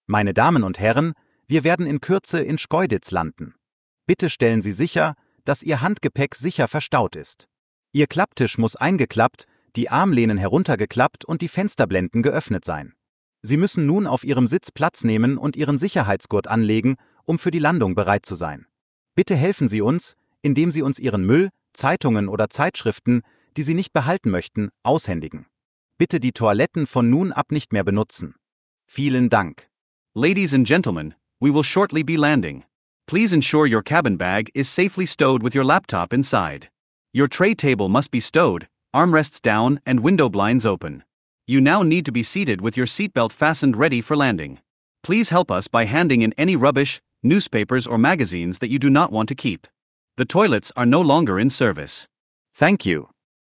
DescentSeatbelts.ogg